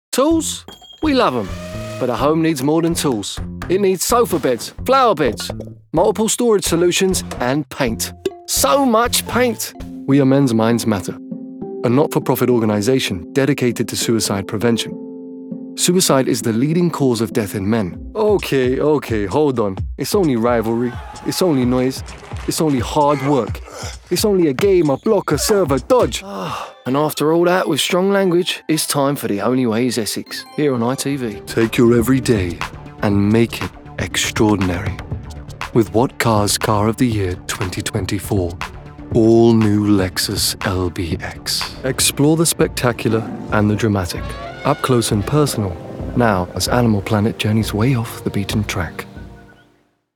His voice is a perfect fit for both bubbly and more serious reads.
Voice Reels
Voice Type Relatable Youthful Versatile Gaming/character
Natural accents London, Neutral